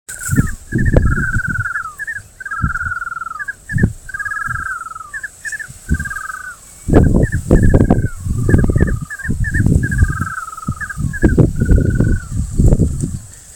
Ash-throated Crake (Mustelirallus albicollis)
Life Stage: Adult
Country: Brazil
Location or protected area: Rio largo
Condition: Wild
Certainty: Recorded vocal